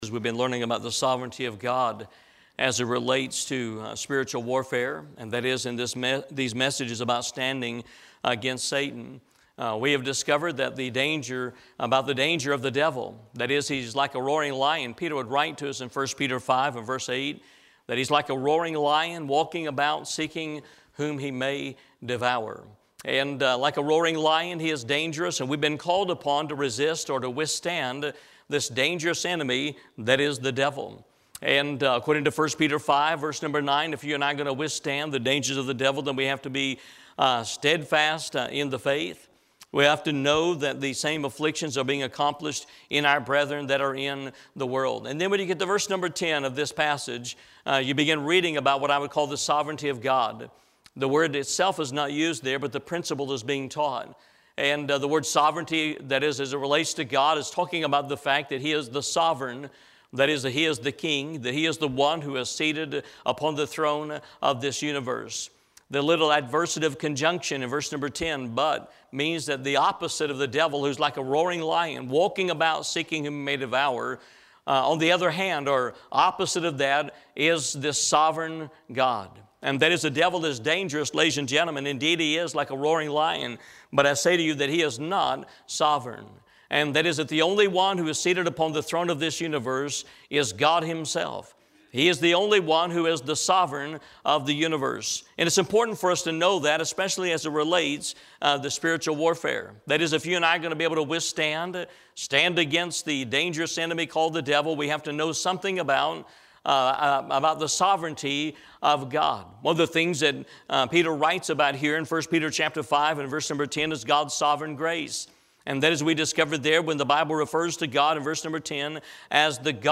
Eastern Gate Baptist Church - Standing Against Satan 53